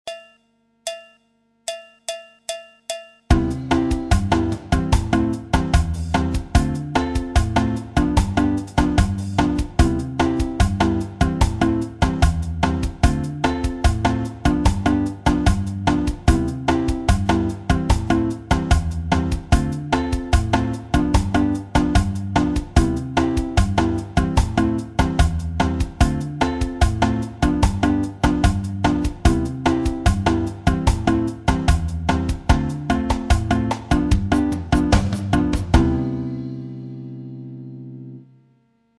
La bossa nova et le partido alto en anatole
Une variante de la figure précédente et que l'on retrouvera dans la samba c'est le Partido alto qui se caractérise par des accents bien particulier. Pour le mettre en valeur on utilise les cordes aigues de la guitare.